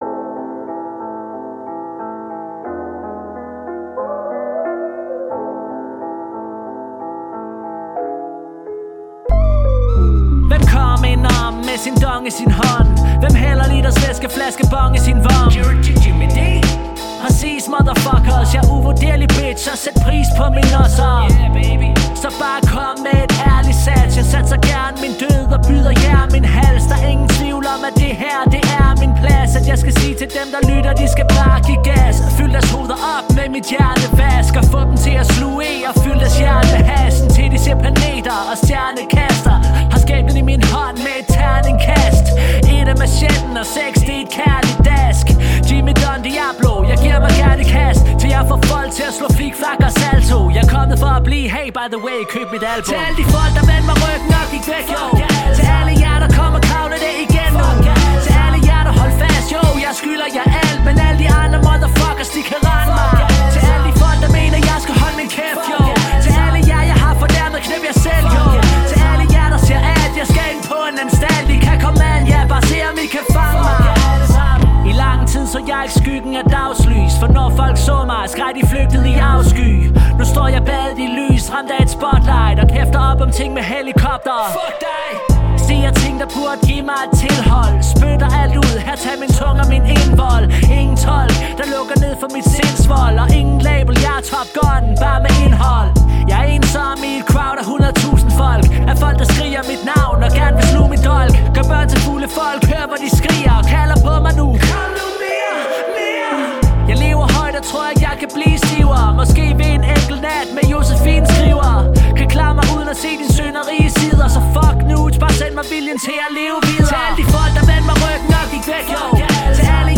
• Hip hop